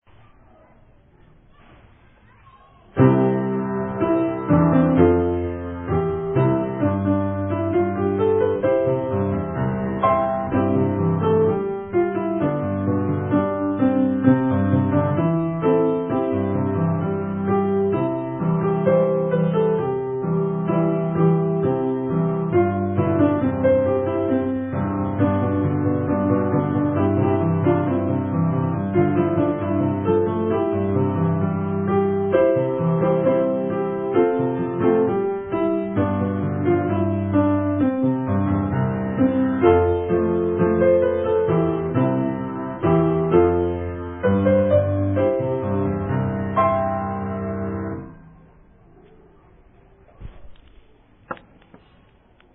校歌伴奏.mp3